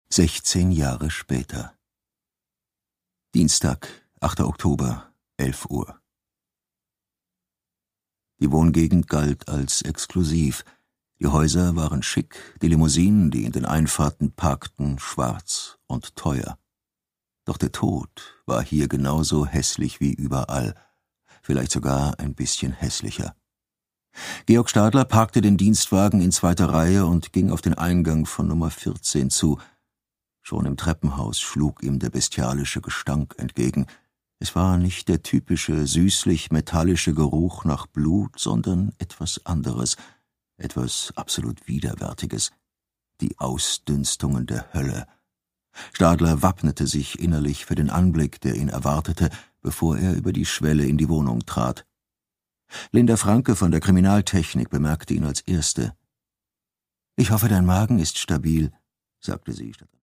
Produkttyp: Hörbuch-Download